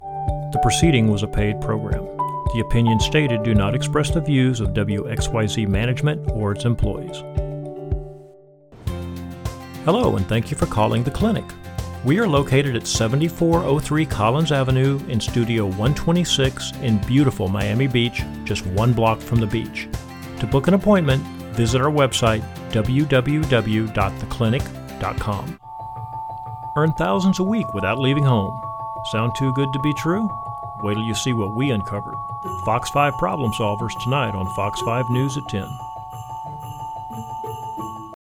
Originally from Texas, I have a faint accent that can be amped up when needed.